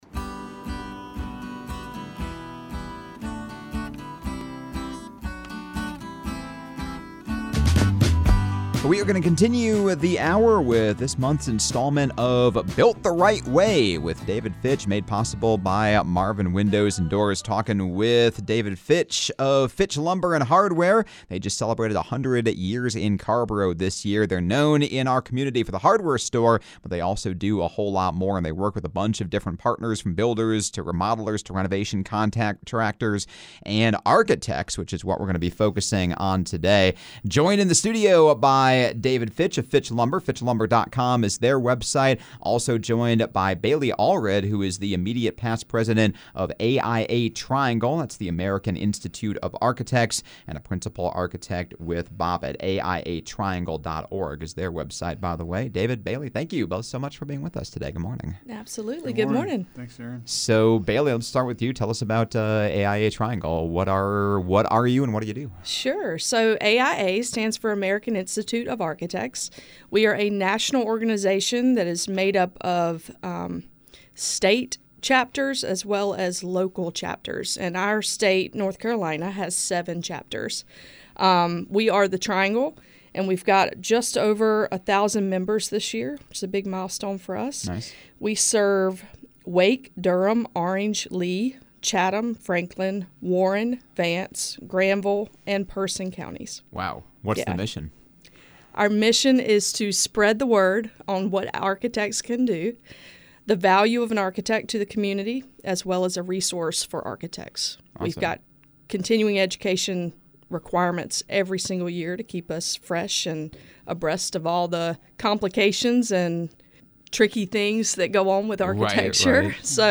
“Built The Right Way” is a bi-monthly interview series sponsored by Marvin Windows and Doors, featuring interviews with experts involving in home building, development, construction, architecture and everything in between!